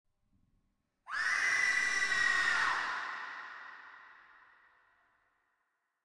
Download Scream sound effect for free.
Scream